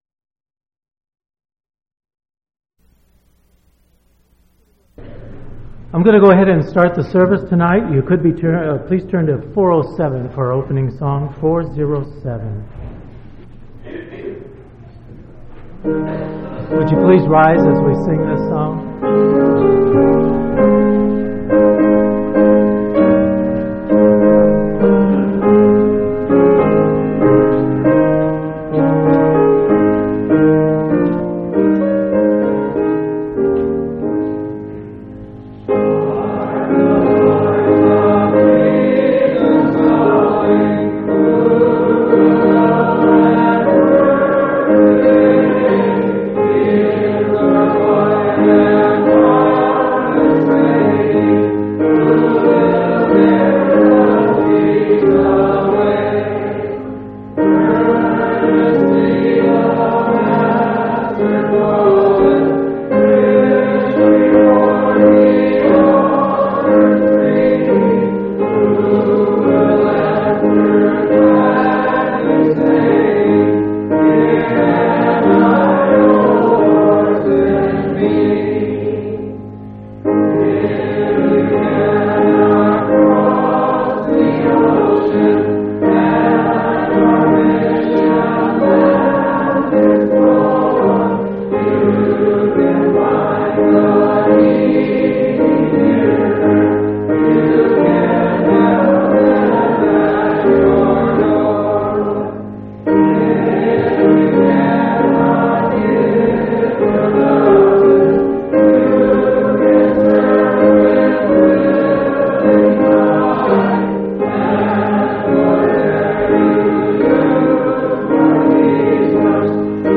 Event: General Church Conference